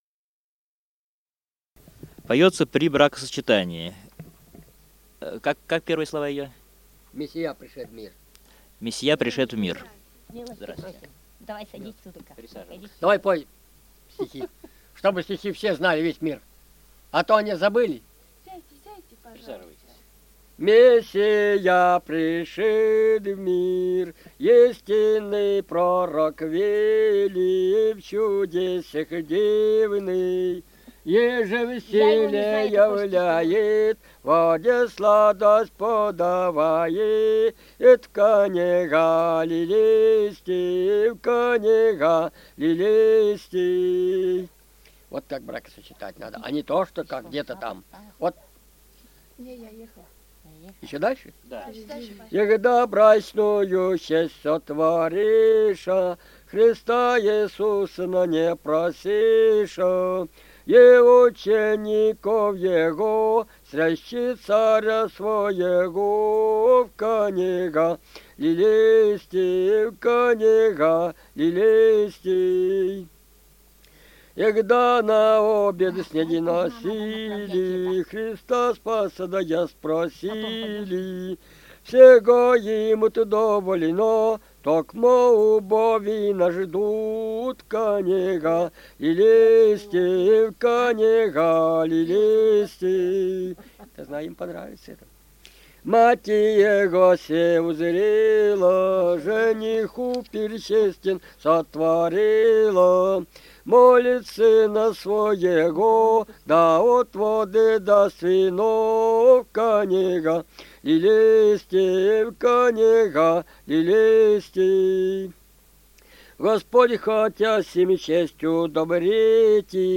Русские песни Алтайского Беловодья 2 «Мессия пришед в мир», духовный стих о претворении воды в вино в Кане Галилейской; поют на бракосочетании.
Республика Алтай, Усть-Коксинский район, с. Тихонькая, июнь 1980.